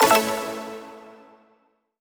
button-solo-select.wav